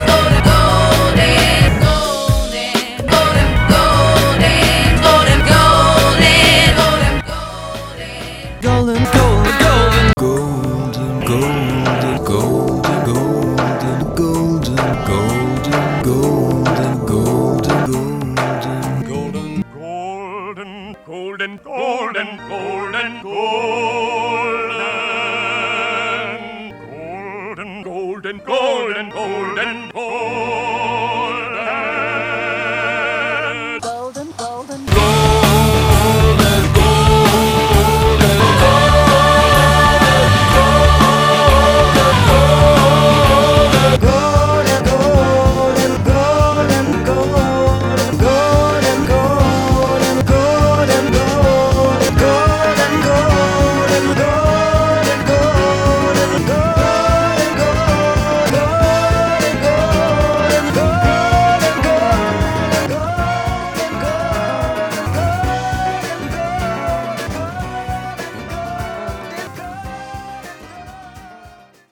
Songs I – V is a series of short sound pieces edited from sung instances of the word ‘golden’, to produce occasional audio interludes, at once plaintive, joyful and wistful.